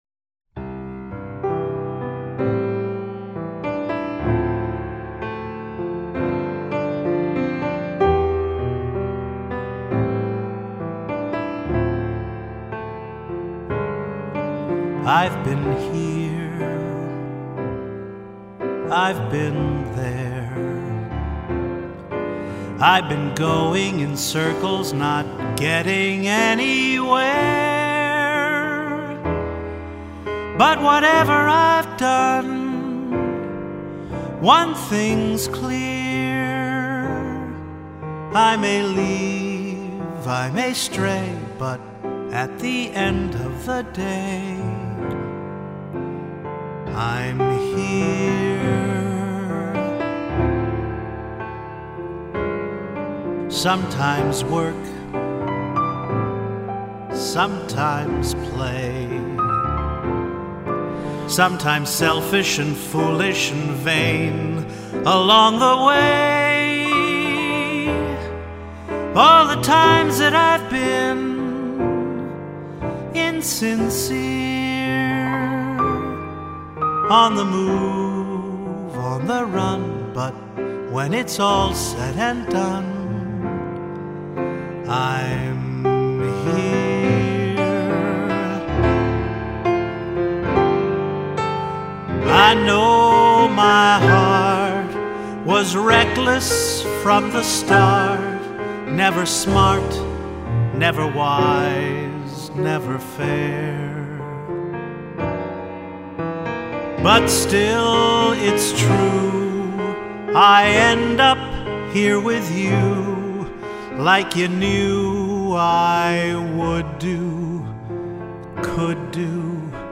Here’s another Writer’s Block song, the ballad from the musical I created for the book – it’s called I’m Here.
That was a ballad, wasn’t it?